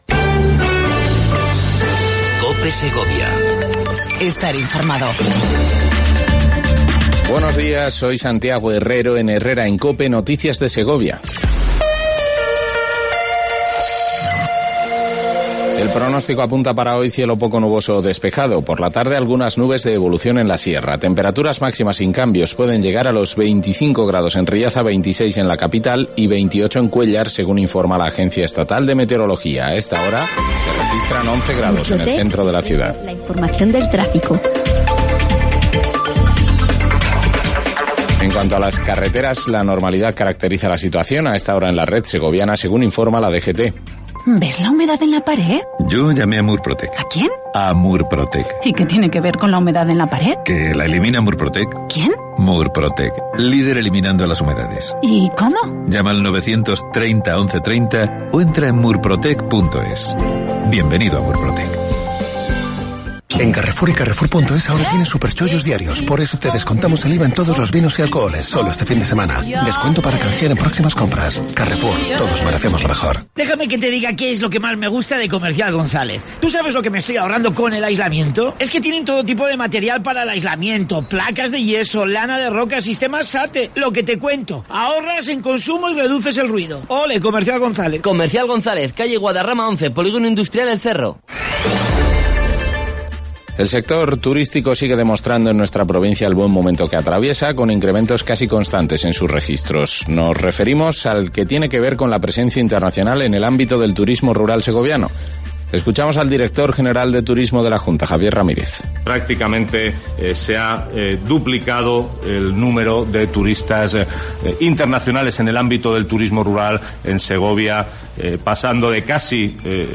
AUDIO: Segundo informativo local cope segovia